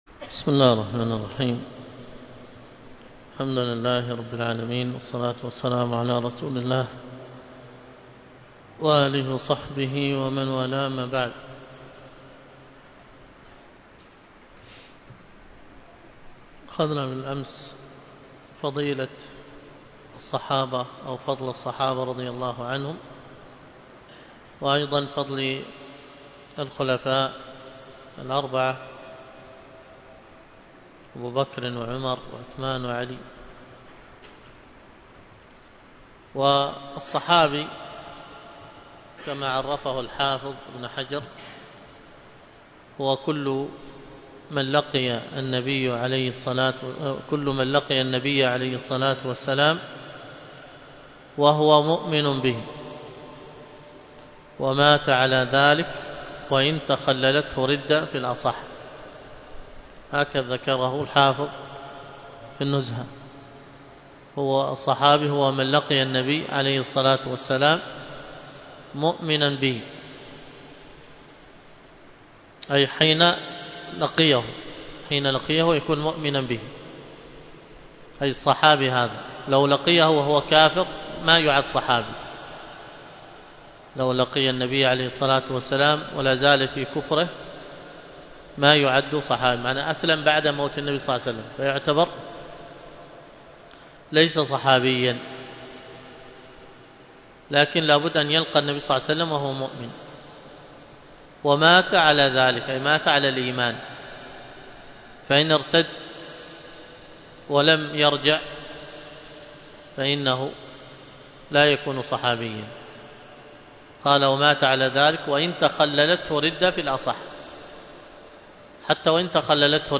الدرس في شرح تحفة الأطفال 10، الدرس العاشر:في المثلين من:(30ـ إن في الصفات والمخارج اتفق*حرفان فالمثلان فيهما أحق...34ـ أو حرك الحرفان في كل فقل*كل كبير وافهمنه بالمثل).